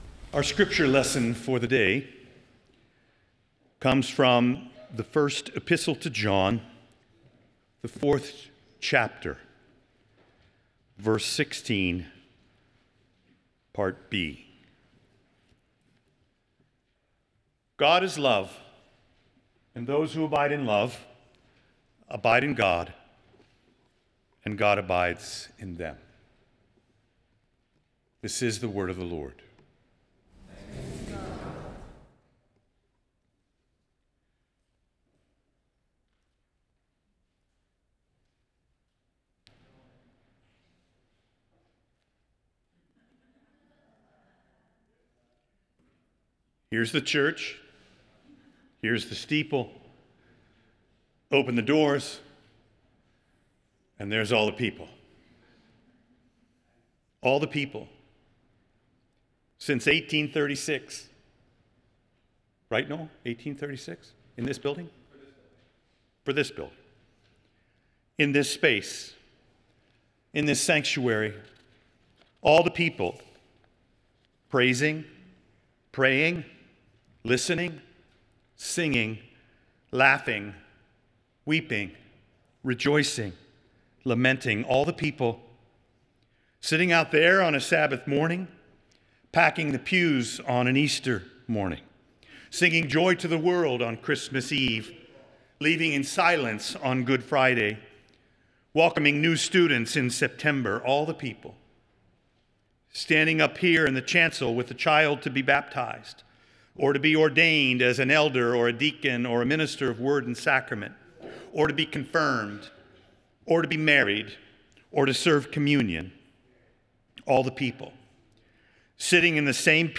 Nassau Presbyterian Church Sermon Journal See All the People Sep 28 2025 | 00:13:40 1x 00:00 / 00:13:40 Subscribe Share Apple Podcasts Spotify Amazon Music Overcast RSS Feed Share Link Embed